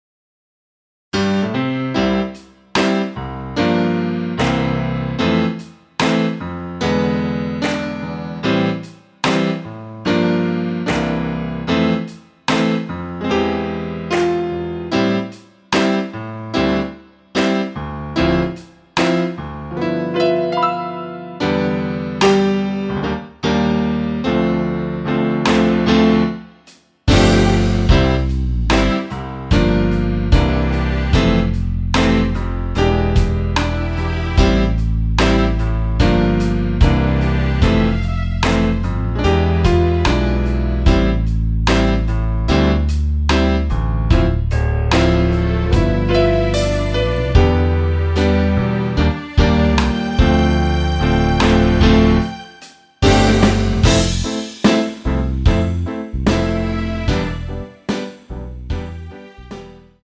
◈ 곡명 옆 (-1)은 반음 내림, (+1)은 반음 올림 입니다.
음정은 반음정씩 변하게 되며 노래방도 마찬가지로 반음정씩 변하게 됩니다.
앞부분30초, 뒷부분30초씩 편집해서 올려 드리고 있습니다.
중간에 음이 끈어지고 다시 나오는 이유는